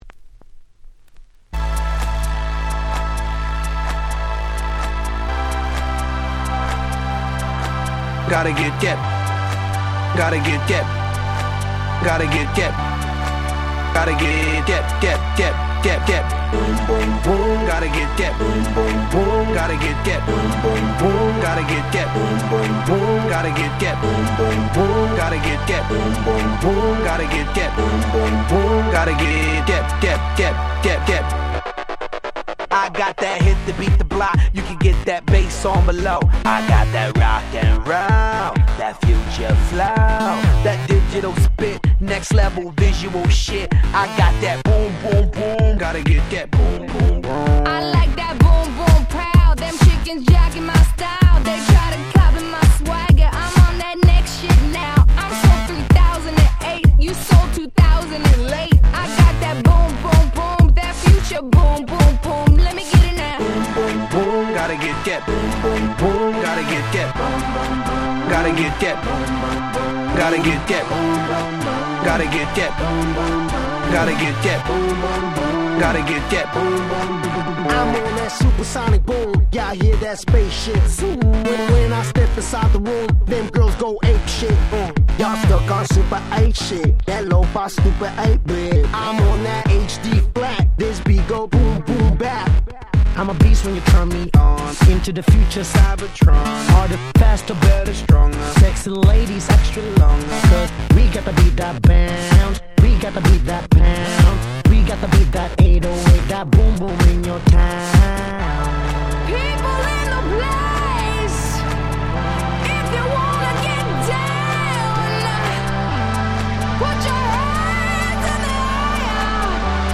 09’ Super Hit R&B / Hip Hop !!
EDMとクロスオーバー気味だった頃の彼らのスーパーヒット！！